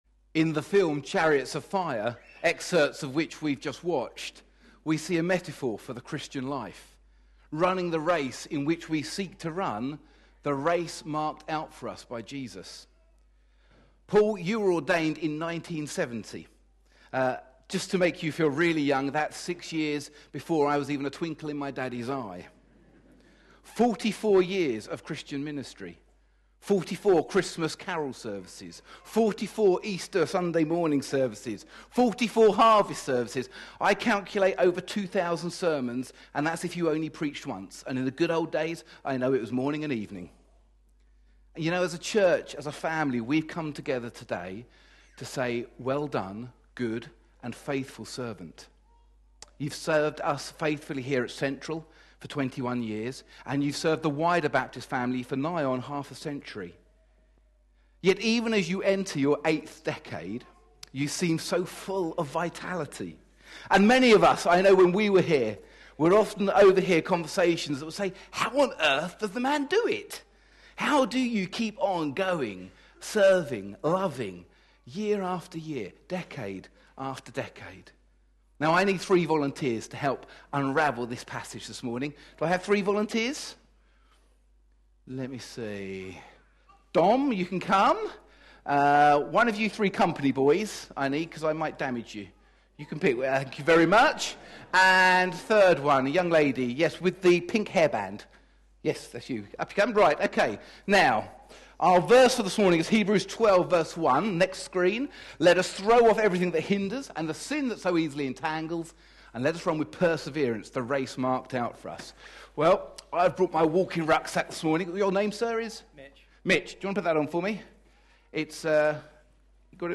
A sermon preached on 16th March, 2014.